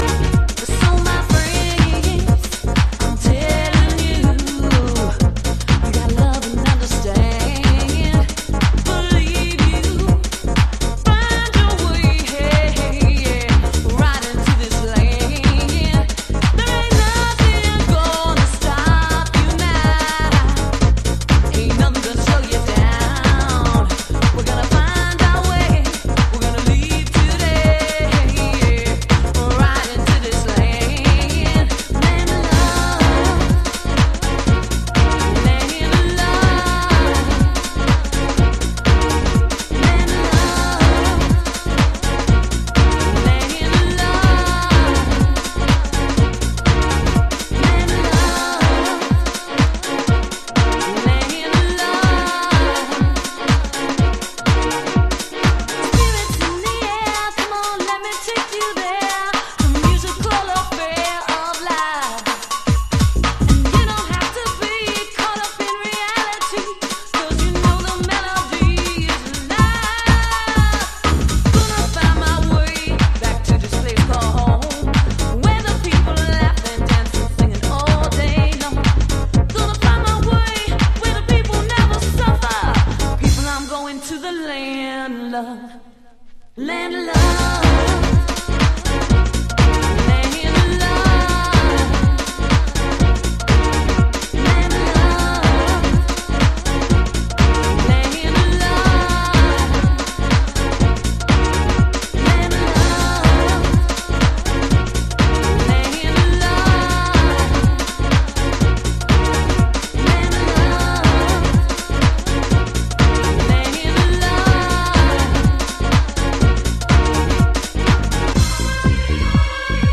House / Techno
ベースが強調されたディープハウス〜ガラージハウス。